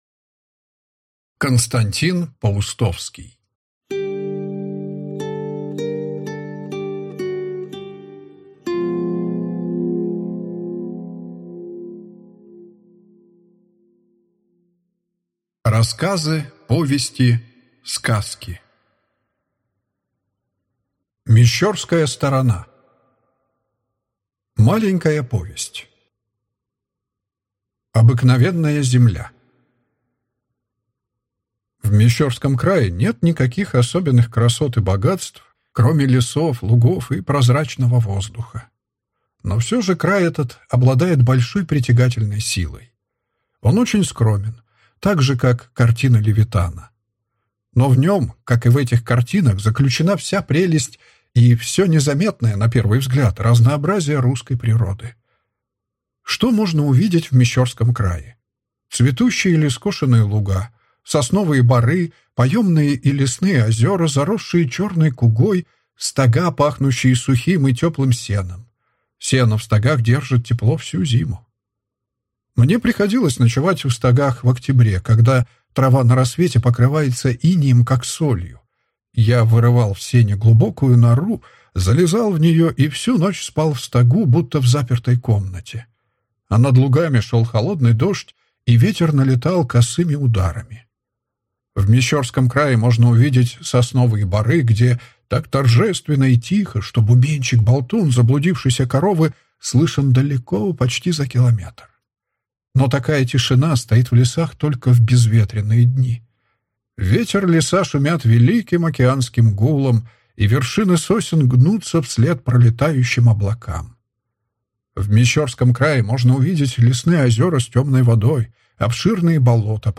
Аудиокнига Рассказы. Повести. Сказки | Библиотека аудиокниг